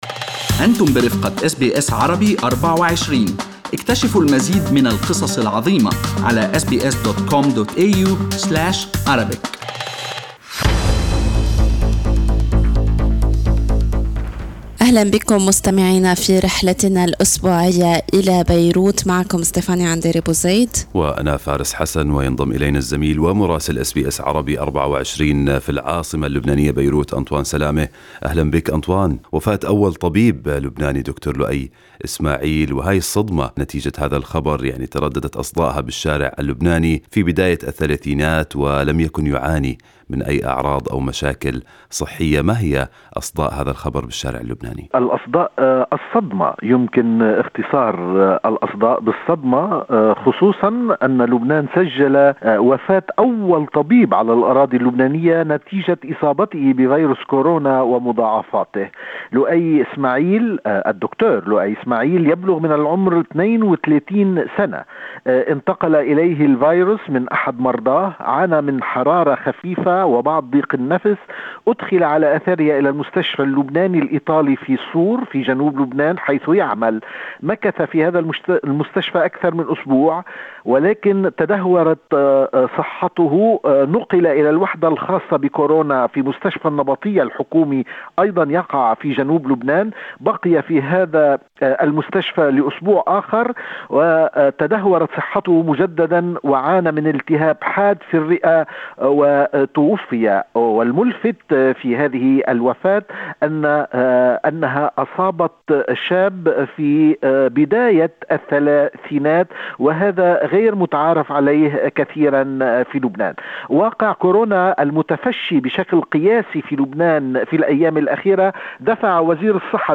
من مراسلينا: أخبار لبنان في أسبوع 21/07/2020